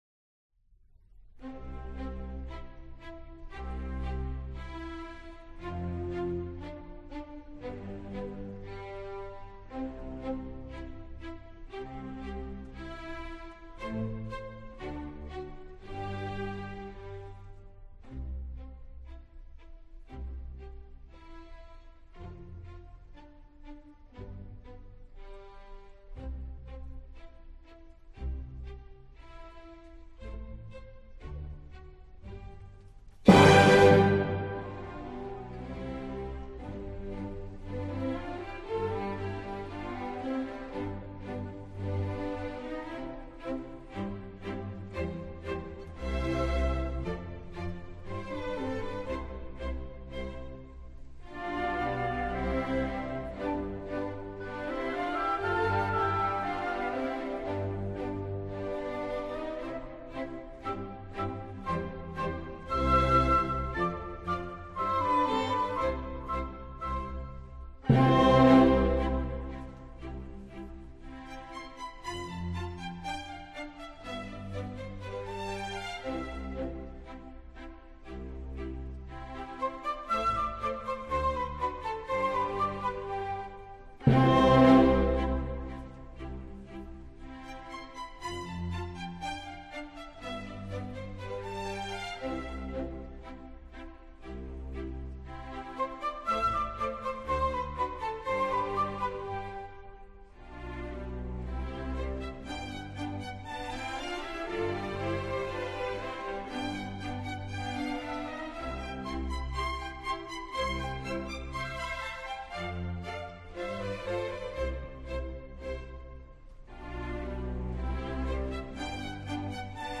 Orchestre symphonique